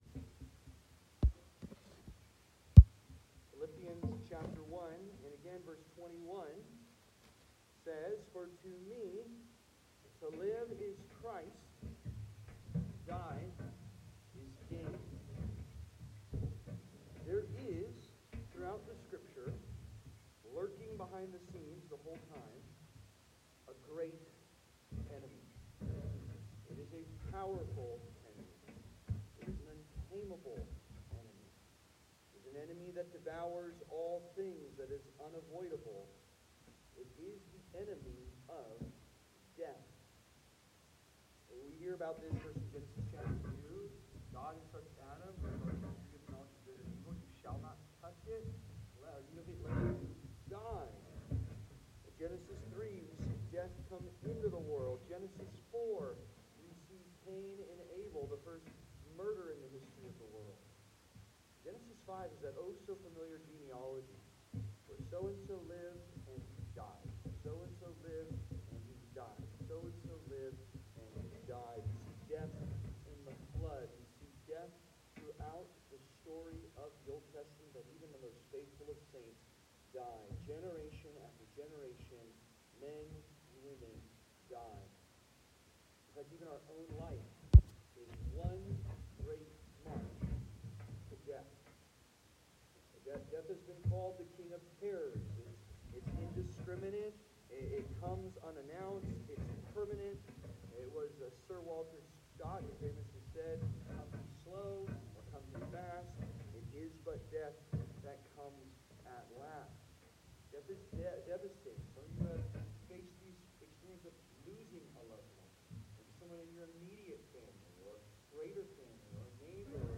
Life Undivided - To Die is Gain - Session 3 (Sermon) - Compass Bible Church Long Beach